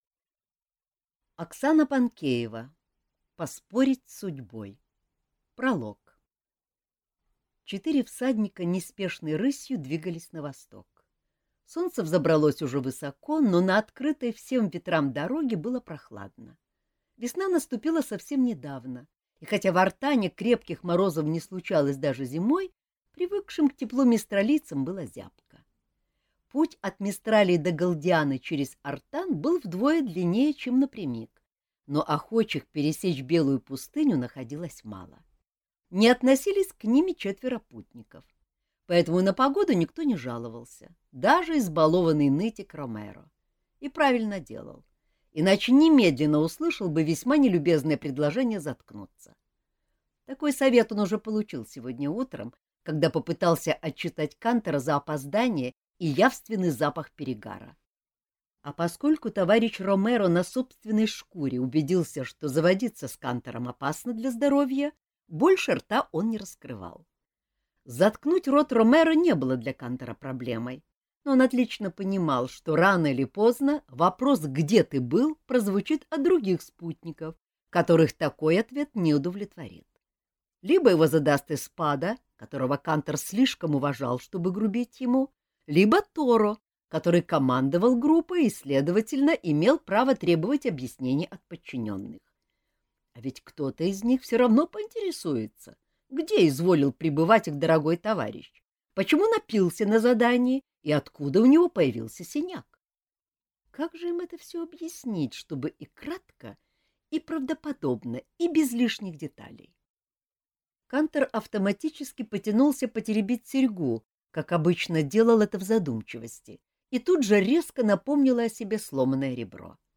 Аудиокнига Поспорить с судьбой | Библиотека аудиокниг